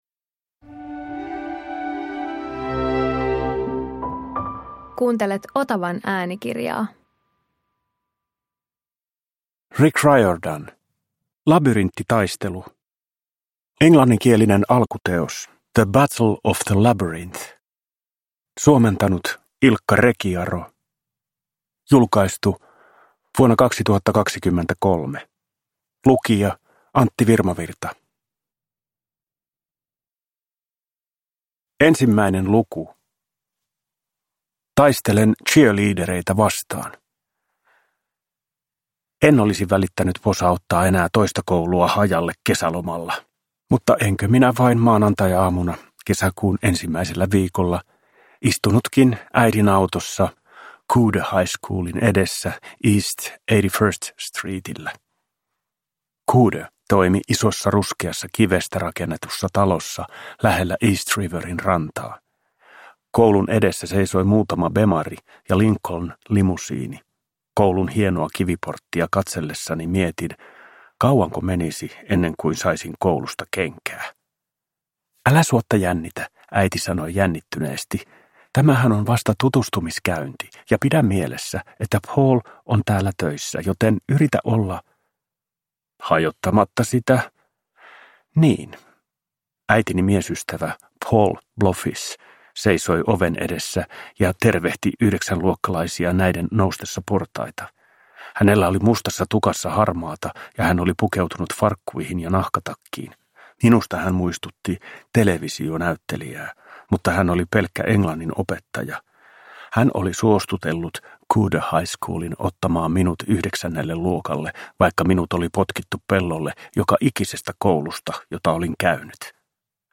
Labyrinttitaistelu – Ljudbok – Laddas ner